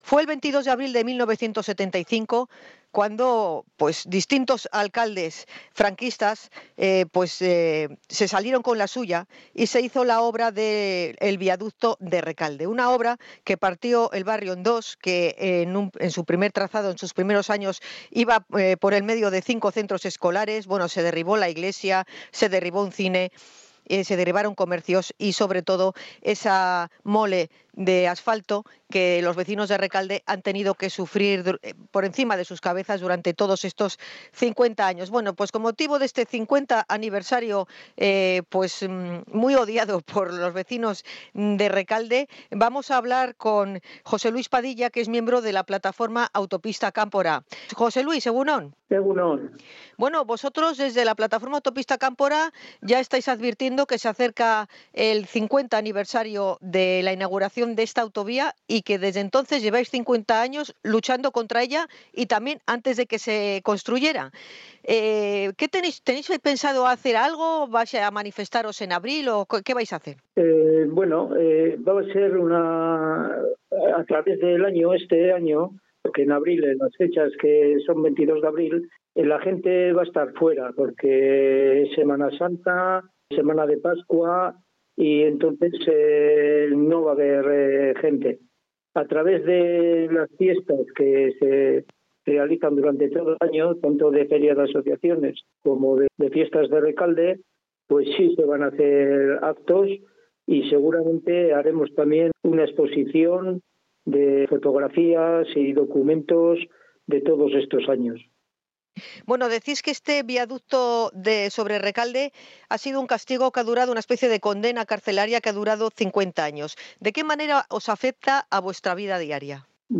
INT.-ANIVERSARIO-VIADUCTO-REKALDE-2025.mp3